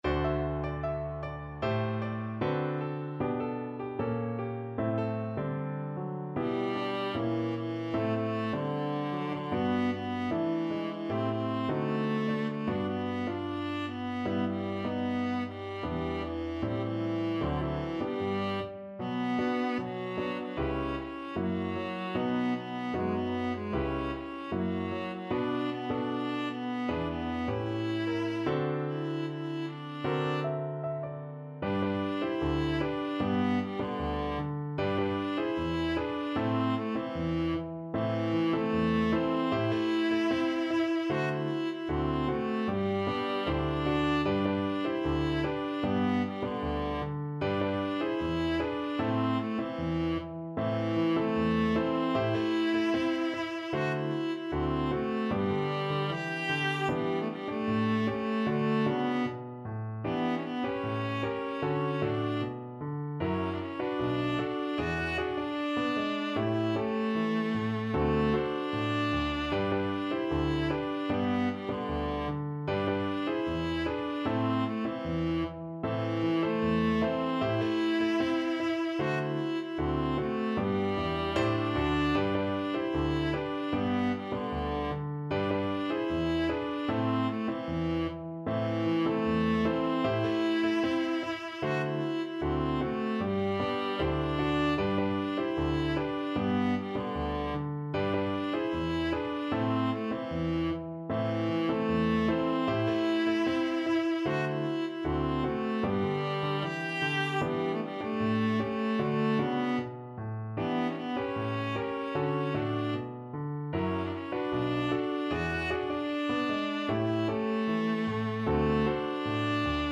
2/2 (View more 2/2 Music)
D4-G5
Pop (View more Pop Viola Music)